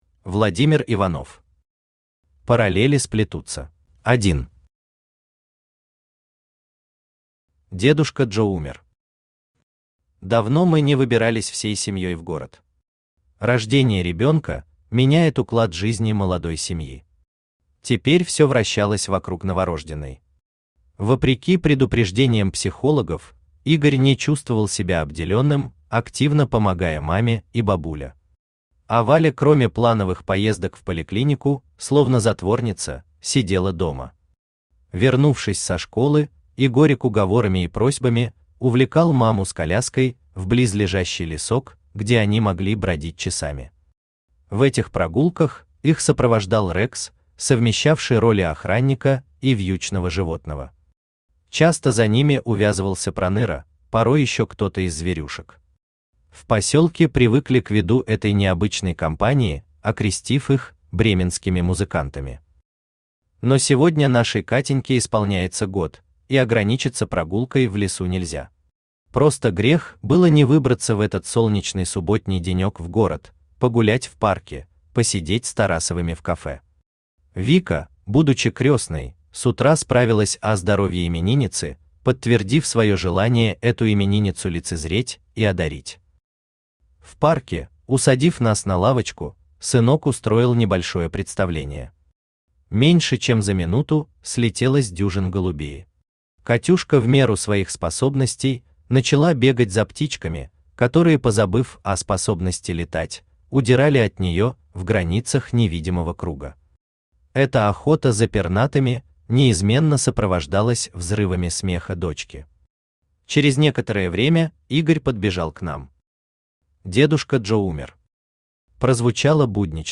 Аудиокнига Параллели сплетутся | Библиотека аудиокниг
Aудиокнига Параллели сплетутся Автор Владимир Иванович Иванов Читает аудиокнигу Авточтец ЛитРес.